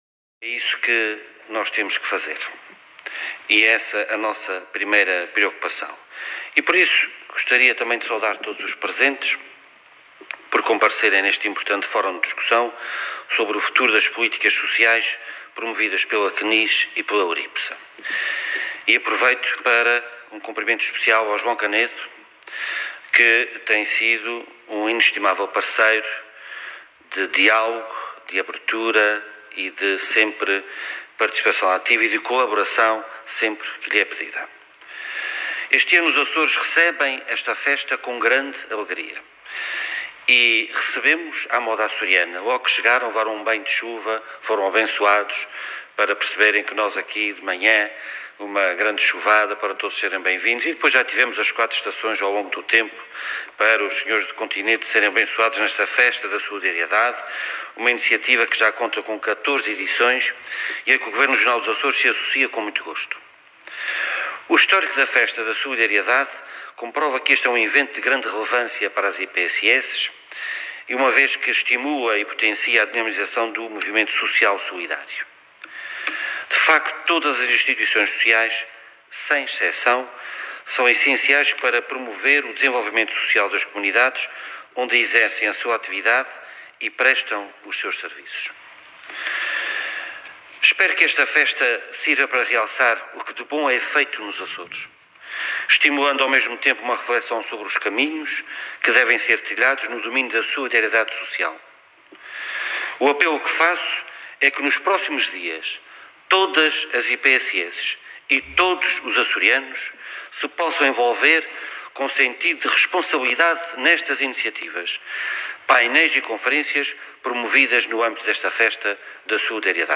Artur Lima falava na sessão de encerramento da conferência sobre a economia social, que decorreu no âmbito da Festa da Solidariedade Açores 2021, organizada pela CNIS e pela URIPSSA.